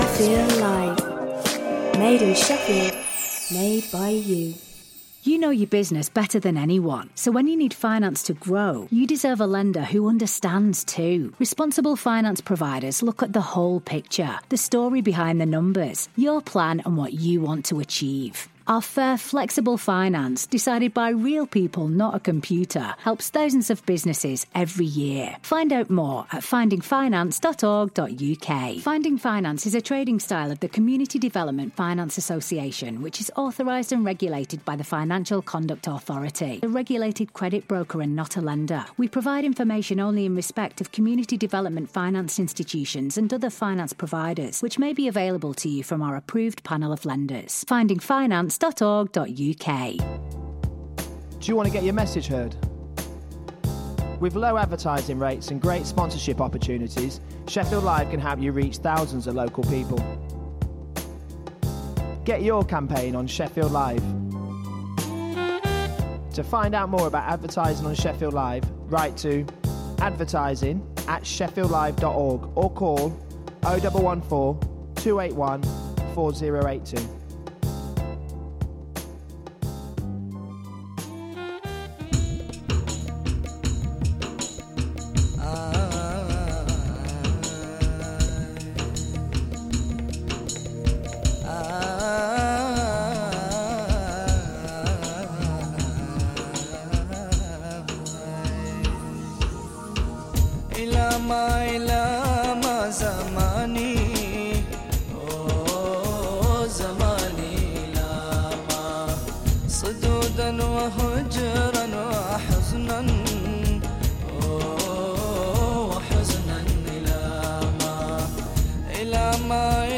Write Radio is a two hour radio show which showcases new and local writing from the people of South Yorkshire.